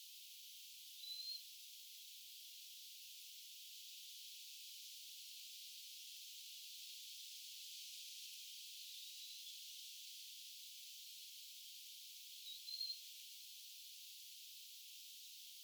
onko ruskosuohaukan poikanen?
Samassa suunnassa äänteli tuo luhtakanakin.
onko_ruskosuohaukan_poikanen.mp3